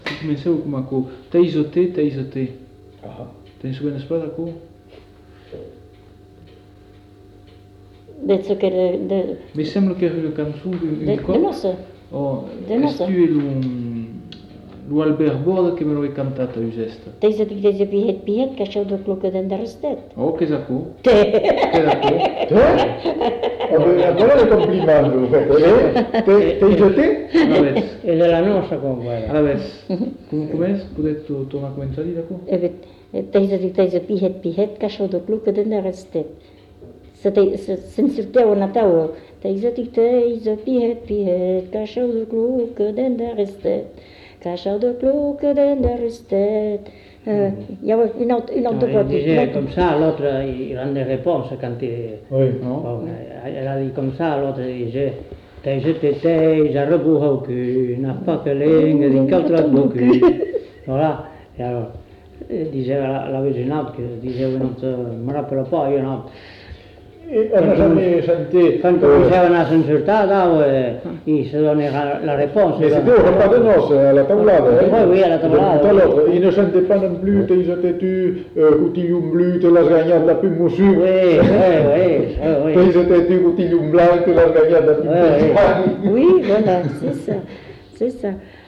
Aire culturelle : Bazadais
Lieu : Cazalis
Genre : chant
Effectif : 2
Type de voix : voix de femme ; voix d'homme
Production du son : chanté